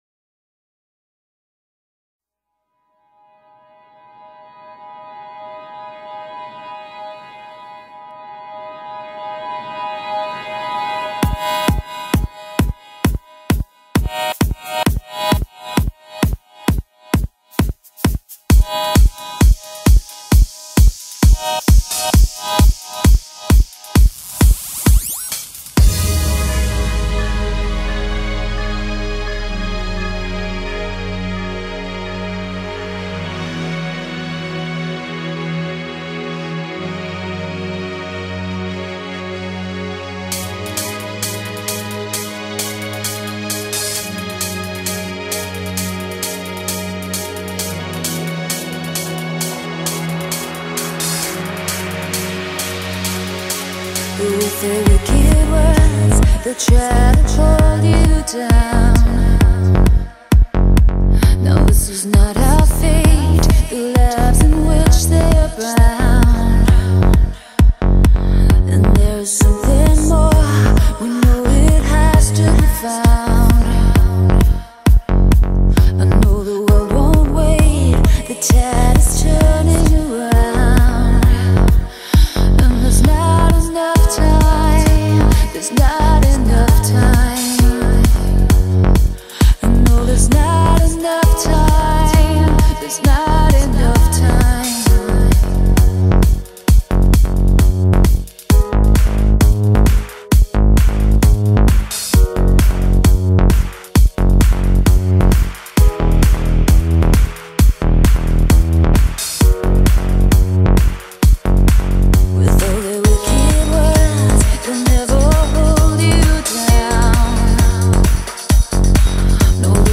vocal trance